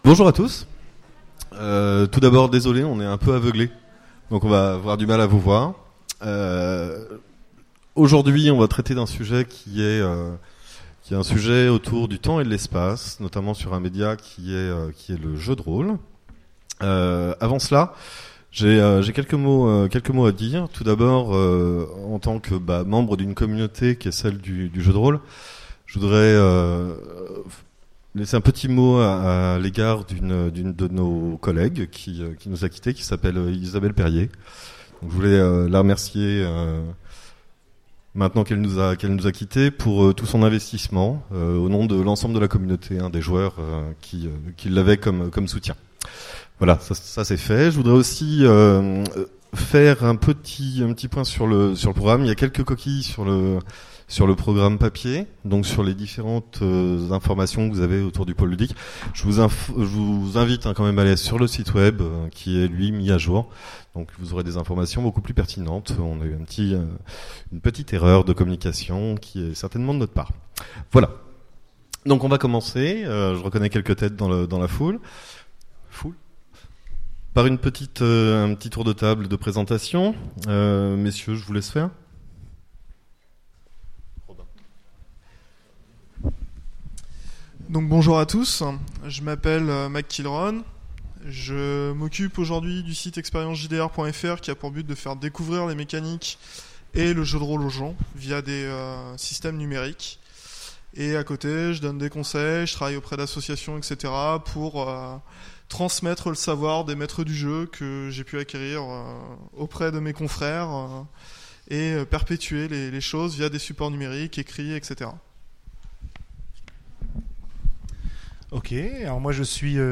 Utopiales 2017 : Conférence Univers intemporels : jouer avec le temps et l’espace